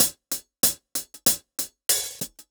Index of /musicradar/ultimate-hihat-samples/95bpm
UHH_AcoustiHatA_95-05.wav